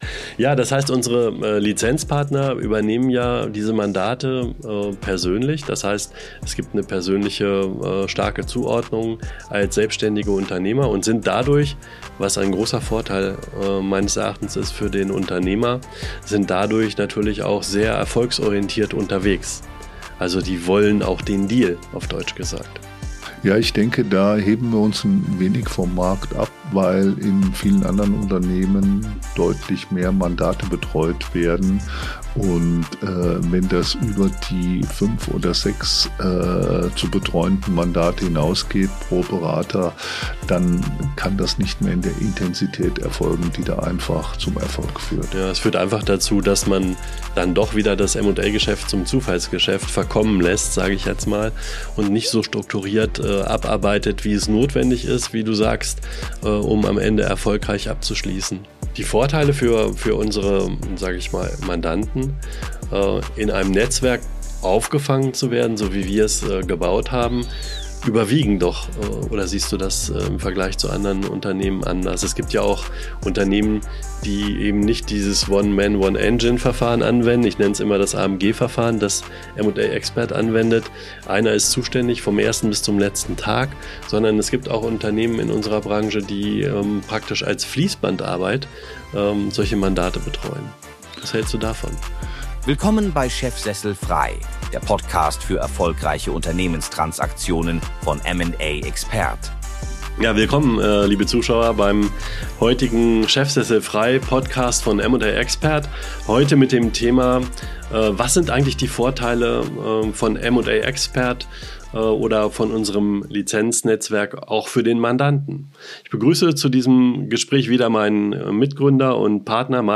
Ein ehrliches, analytisches Gespräch über Systemdenken im M&A, über Erwartungen und Realität im Unternehmensverkauf – ruhig, sachlich und mit klarer Haltung.